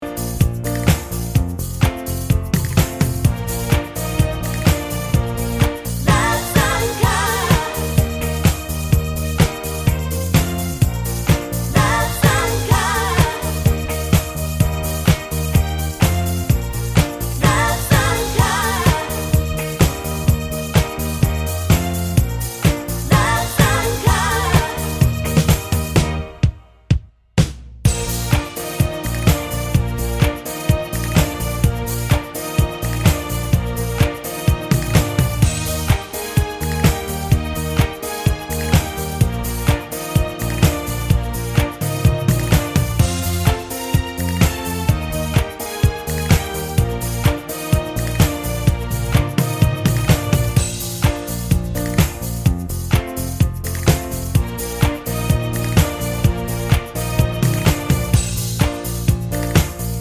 Minus Sax Disco 4:29 Buy £1.50